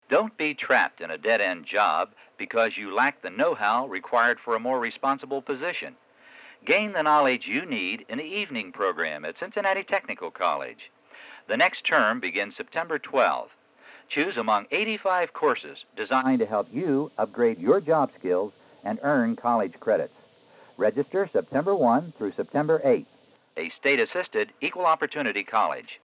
We have so much of our early advertising on reel to reel, cassette, and CD, as well as film, video and DVD.